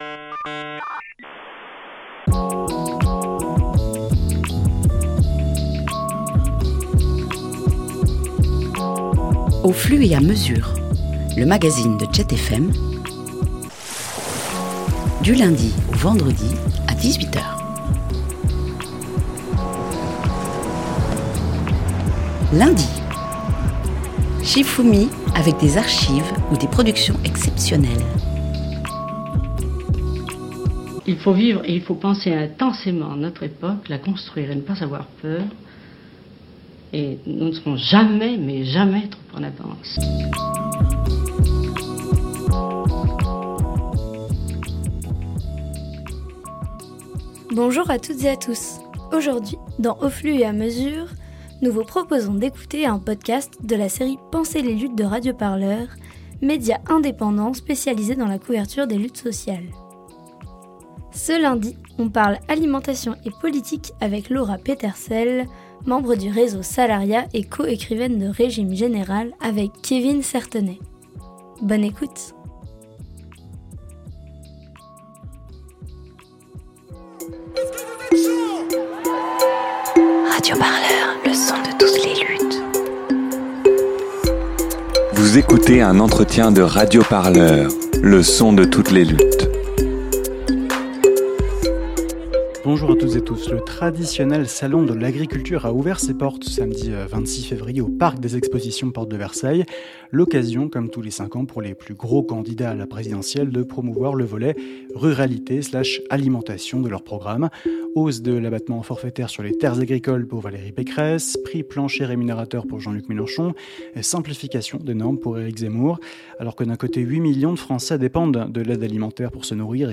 Dans cette série, on interroge des intellectuel-les, des militant-es chevronné-es, des artistes ou encore des chercheur-euses pour parler des luttes de France et d’ailleurs.